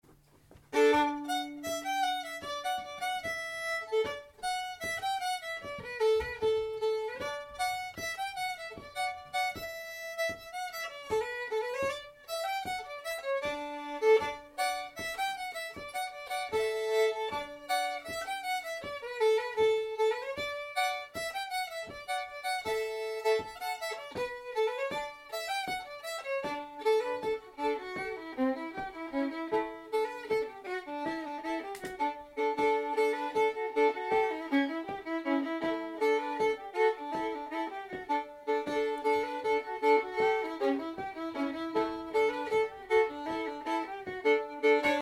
An archive of fiddle and dance tunes, both traditional and new, from Scandinavia, England, Ireland, Scotland, Cape Breton, New Brunswick, Quebec, New England, Appalachia and more, for traditional musicians.
Key: D Form: Reel MP3
Played slowly for learning
La-danse-des-foins-slow.mp3